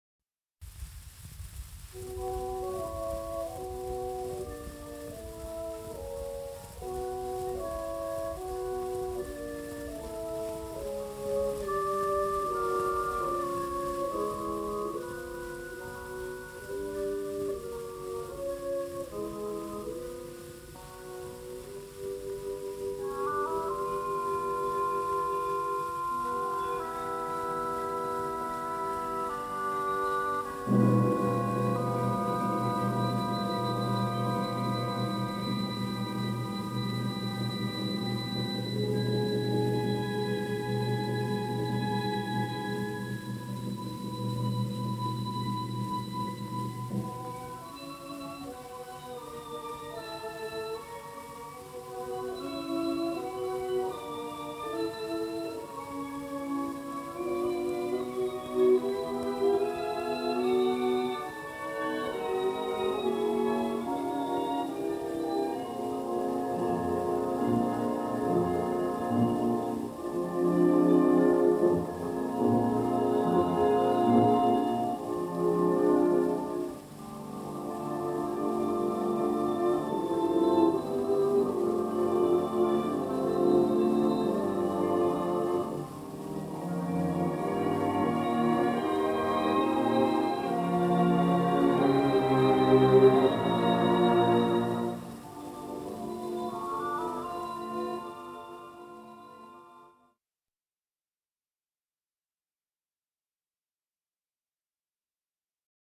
Debussy’s Nuages recorded with the Staatskapelle in 1926 is affectingly plaintive and fragile, calling to mind as does a Furtwaengler recording the crossing of musical wires in Debussy’s workshop that makes us associate these Clouds with the delicious nebulousness of Pelléas et Mélisande.
An interesting meteorological quirk of this recording, not entirely due, I’m sure, to lax engineering, is the virtual thunder clap of the timpani at bar 7, the timpani’s trill marked ppp in the score but a mezzo forte or more in Klemperer’s apparent estimation.